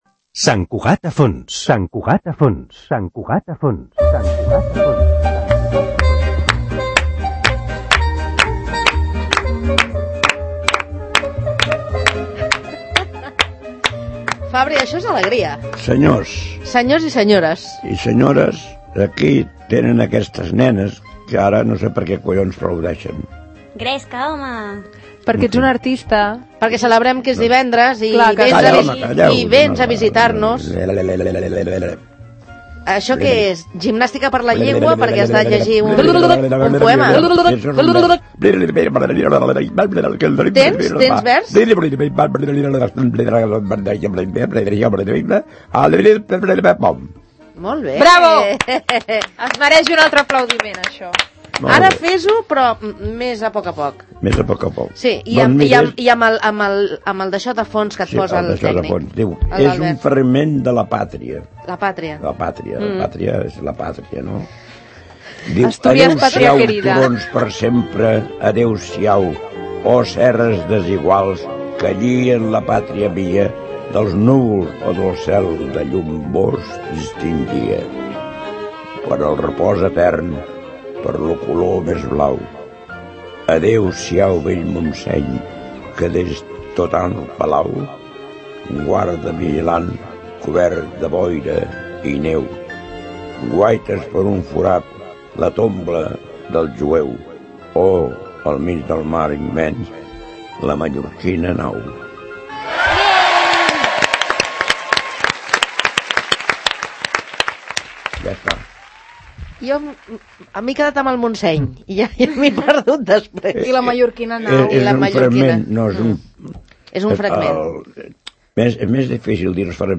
recita un fragment del poema